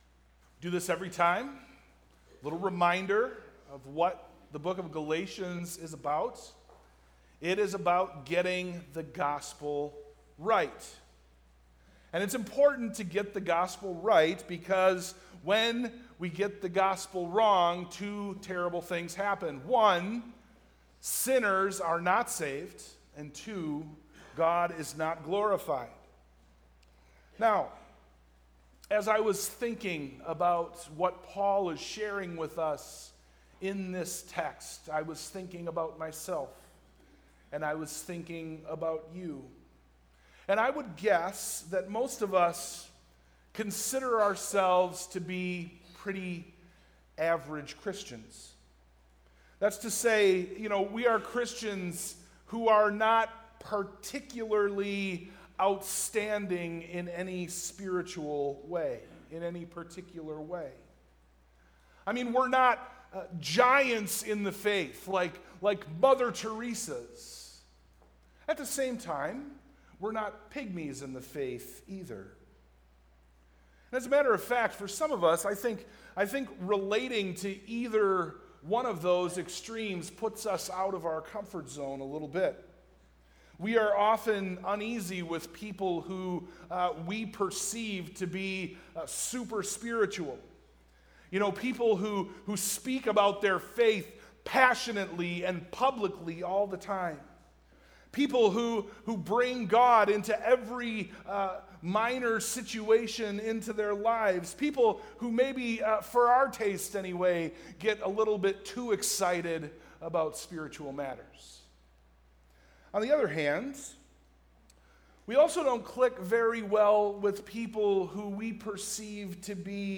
Service Type: PM
Sermon+Audio+-+Sowing+the+Good.mp3